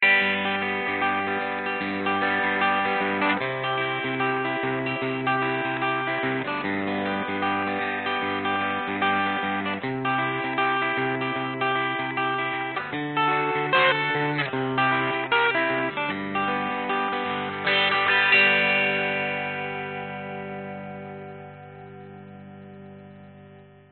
蓝调/C...Riff A 130BPM
描述：围绕ADADAGFA的A调蓝调/乡村节奏130BPM
标签： 蓝调 乡村 电动 吉他
声道立体声